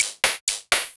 Cyberpunk universal percussion loop for rhythm game. Futuristic, synthetic, glitchy, punchy, genre-neutral (EDM, hip-hop, synthwave). Tempo 125 BPM, clear rhythmic drive, suitable for layering in any style. 0:01 Created Apr 27, 2025 5:32 AM
cyberpunk-universal-percu-5iokkkgp.wav